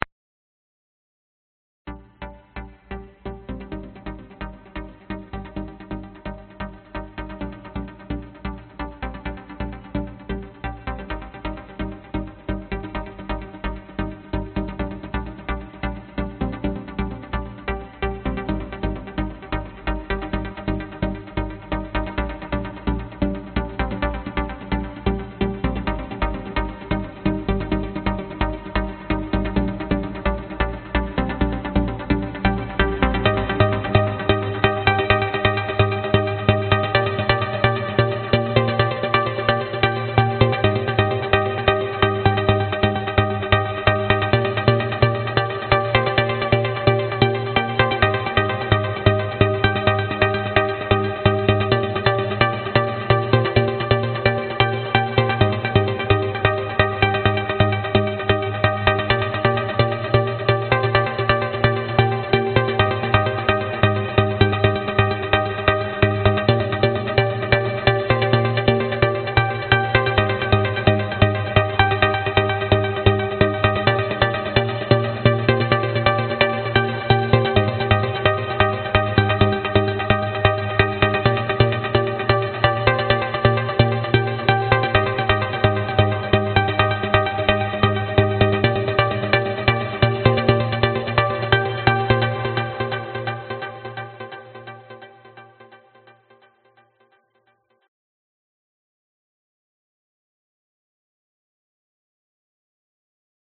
音色和测试 " 粉红噪声
描述：5分钟的粉红噪音
标签： 噪声测试目的 粉色
声道立体声